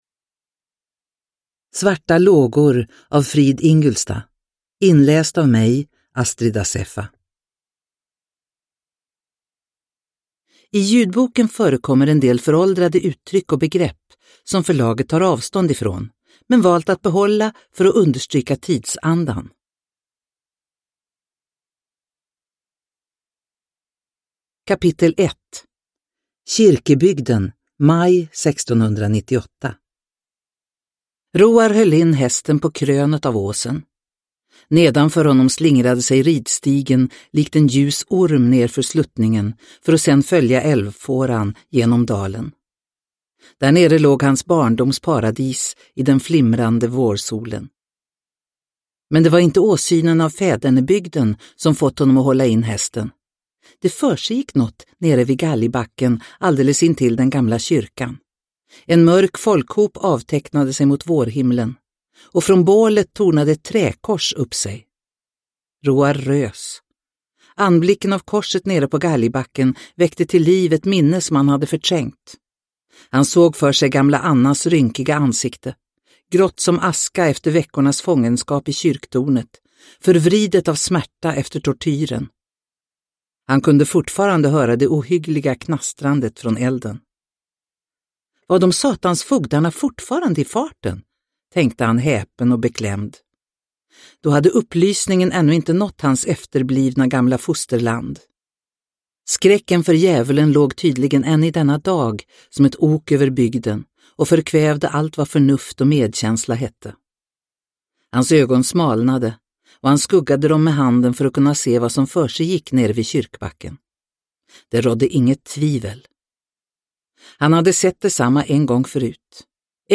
Svarta lågor – Ljudbok – Laddas ner